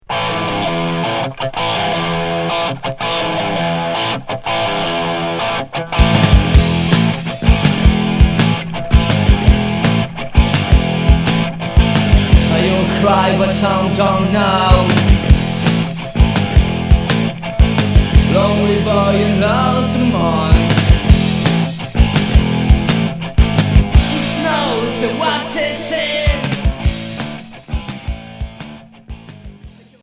Rock punk Unique 45t retour à l'accueil